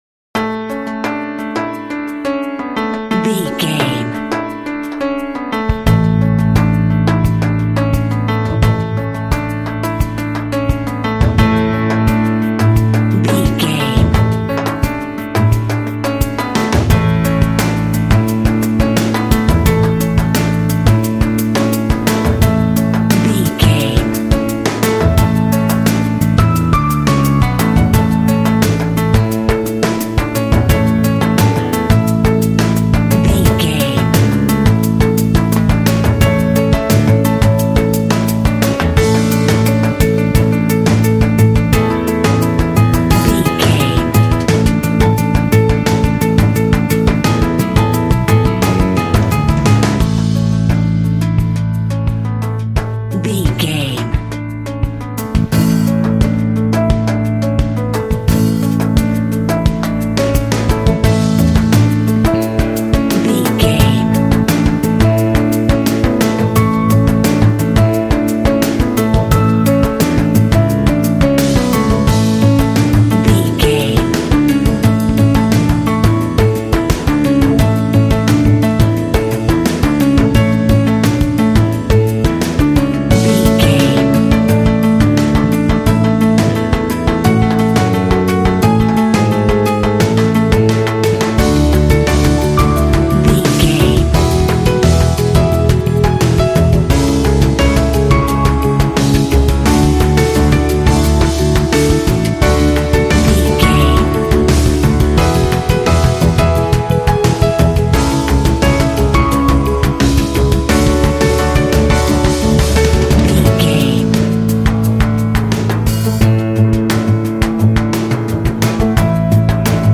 Uplifting
Ionian/Major
inspirational
hopeful
powerful
soothing
piano
drums
bass guitar
electric guitar
cinematic
contemporary underscore